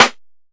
DillaHardSnare.wav